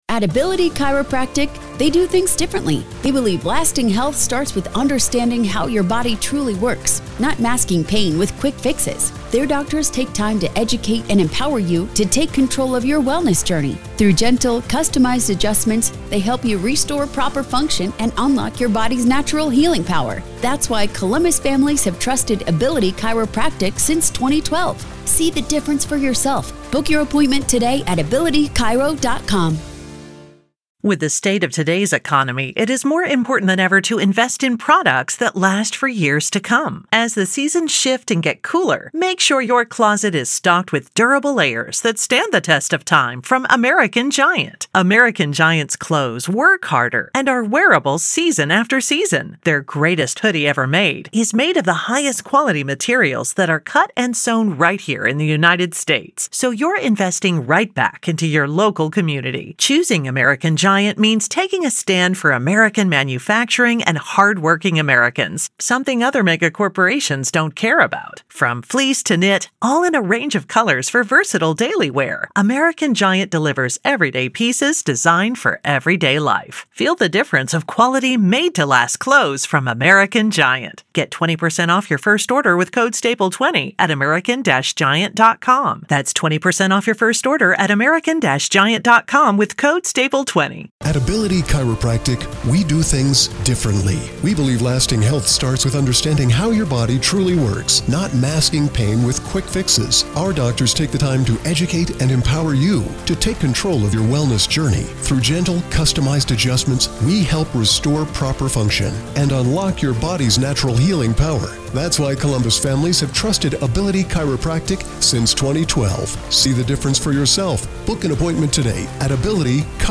In this final chapter of our four-part interview